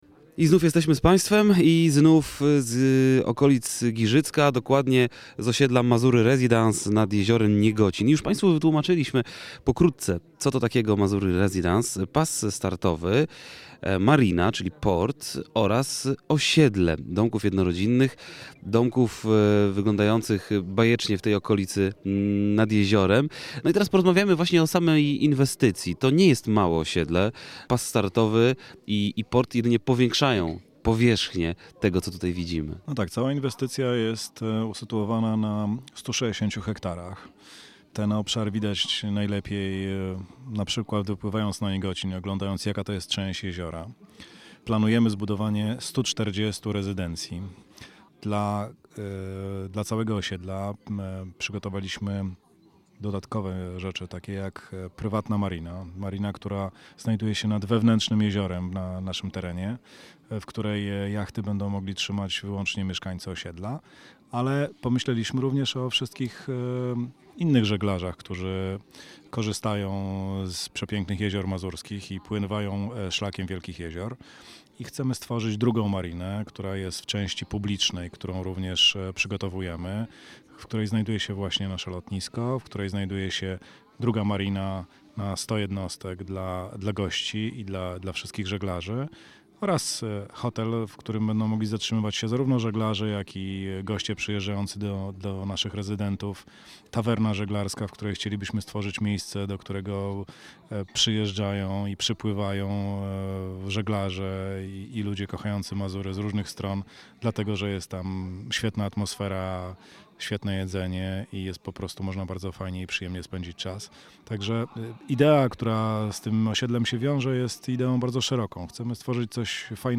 2009-06-13Relacja z Rajdu po Lotniskach i Lądowiskach Warmii i Mazur - jezioro Niegocin, cz.2 (źródło: Radio Olsztyn)